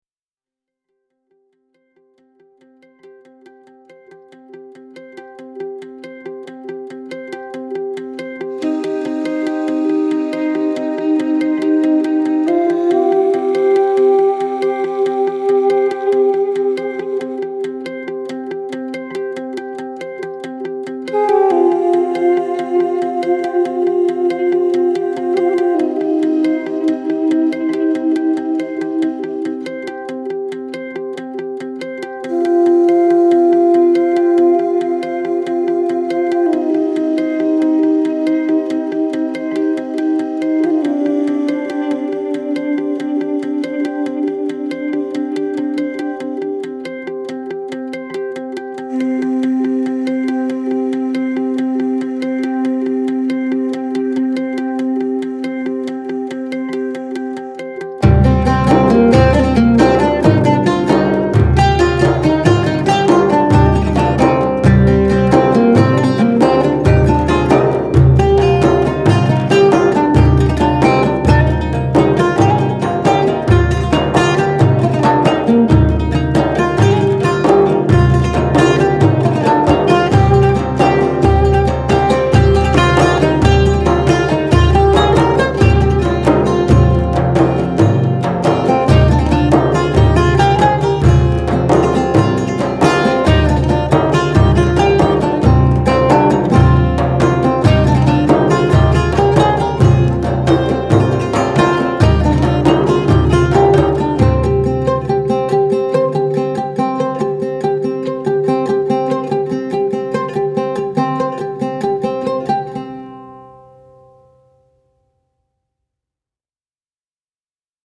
duduk